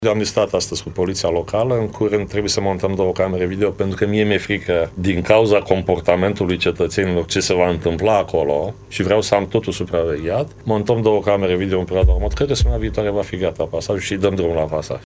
Declarația a fost făcută, la Radio Constanța, de primarul Vergil Chițac: